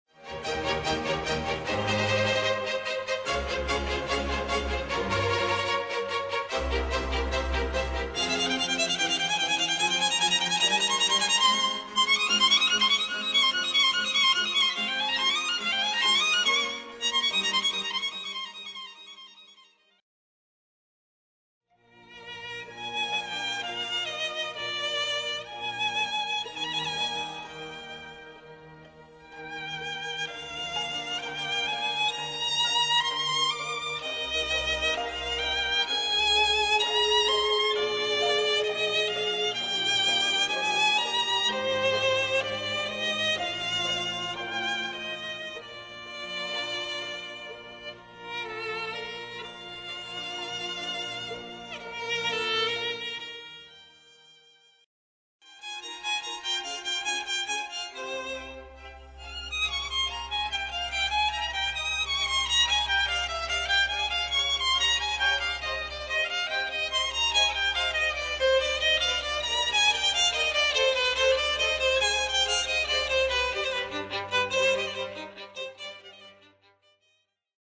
La sequenza propone frammenti dei tre movimenti del Concerto in fa minore - "Inverno" - appartenente a Le quattro stagioni di Antonio Vivaldi.